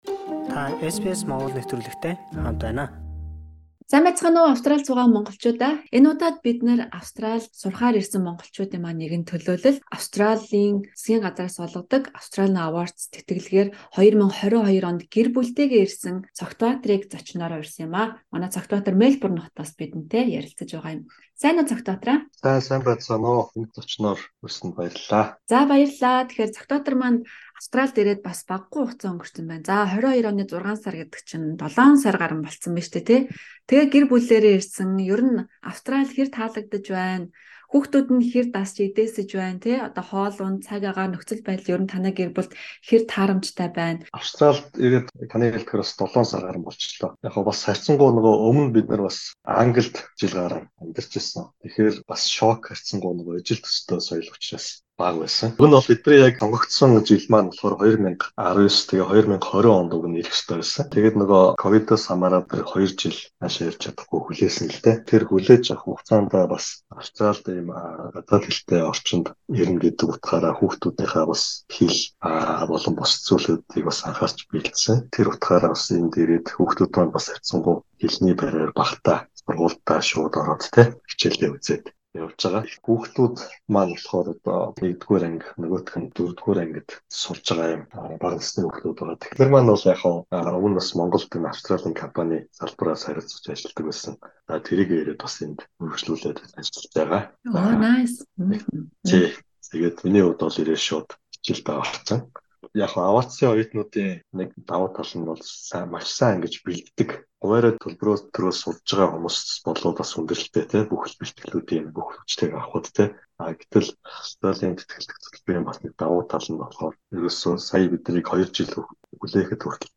Ярилцлагын эхний хэсгийг хүлээн авч сонсоно уу.